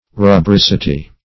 rubricity - definition of rubricity - synonyms, pronunciation, spelling from Free Dictionary Search Result for " rubricity" : The Collaborative International Dictionary of English v.0.48: Rubricity \Ru*bric"i*ty\, n. Redness.
rubricity.mp3